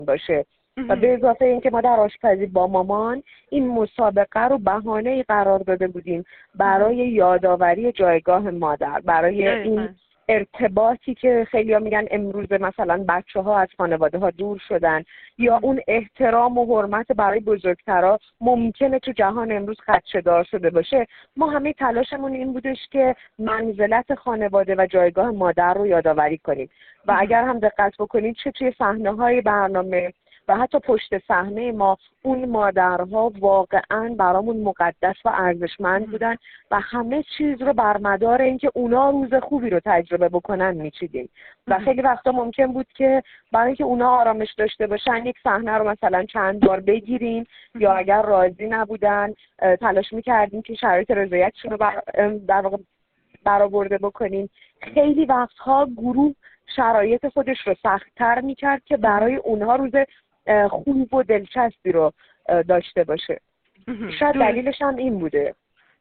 در بخشی از گفت‌وگویش با خبرنگار جام‌ جم